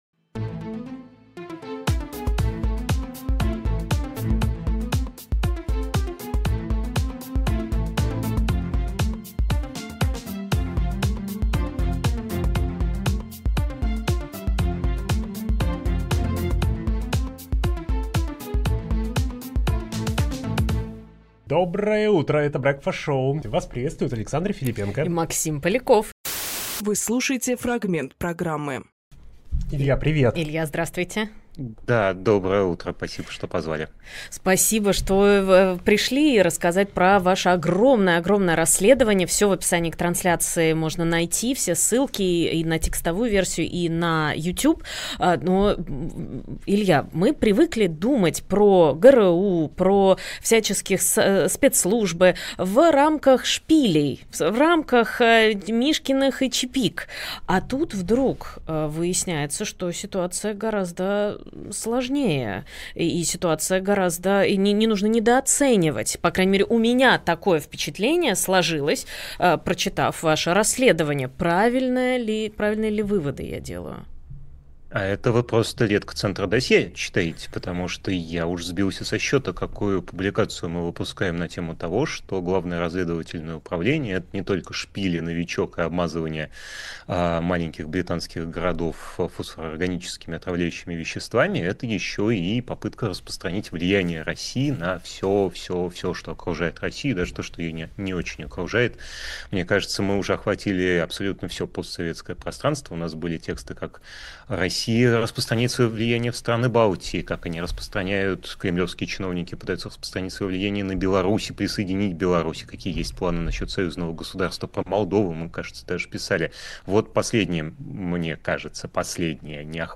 Фрагмент эфира от 12.08.25